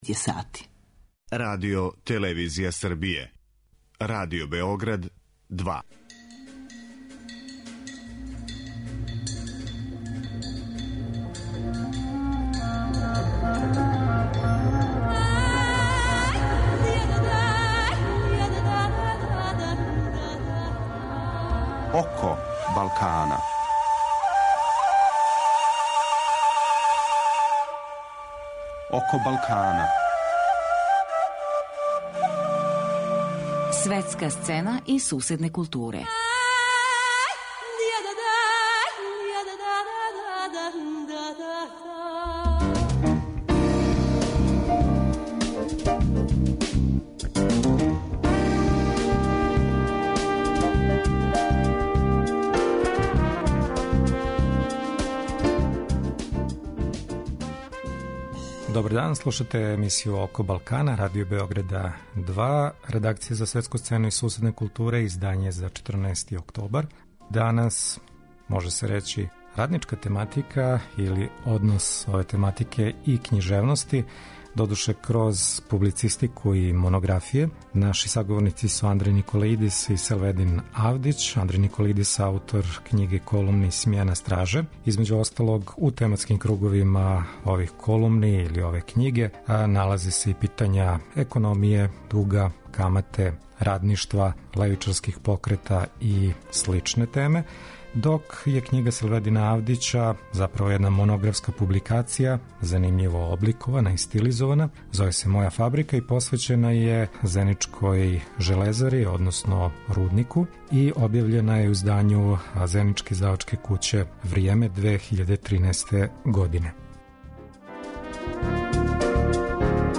Гост емисије је црногорски писац и публициста Андреј Никоалидис. Представићемо његову нову књигу колумни "Смјена страже" (Алгоритам, 2015).